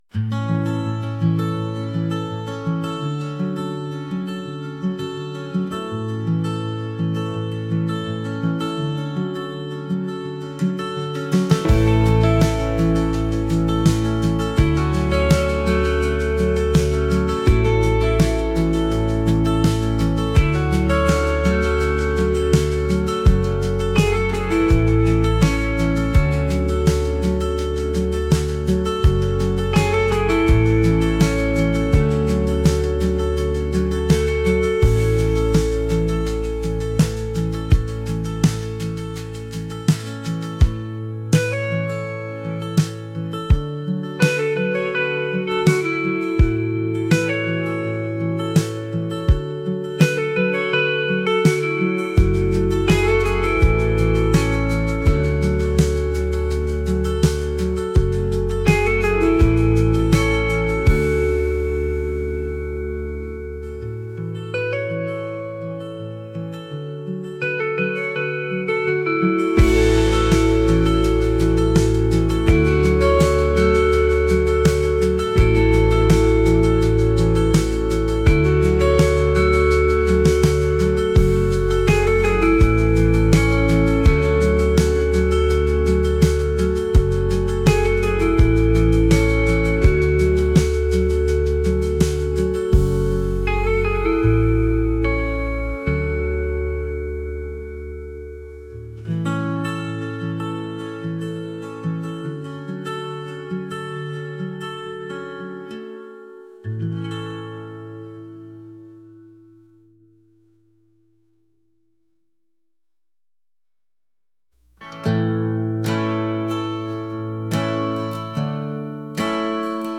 dreamy | indie | folk